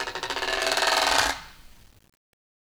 Guiro-Slow_v1_Sum.wav